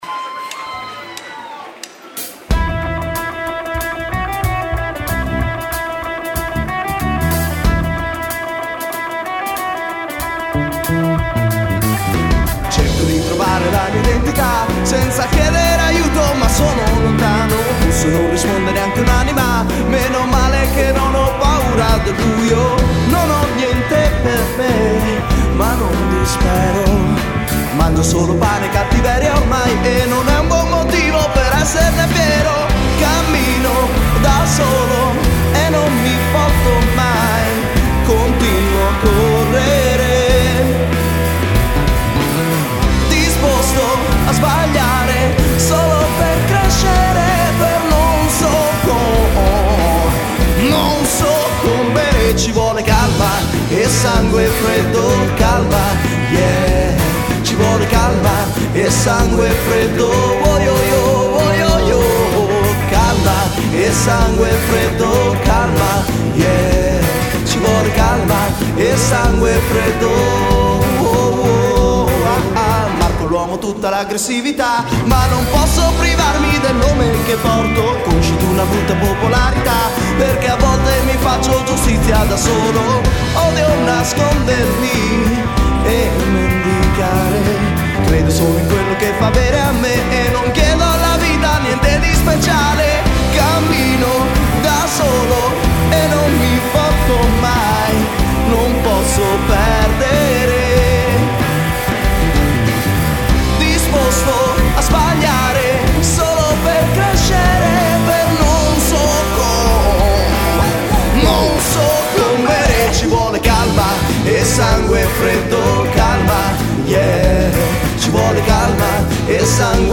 Voce, Chitarra Acustica
Voce, Basso
Chitarre
Batteria
Cover pop-rock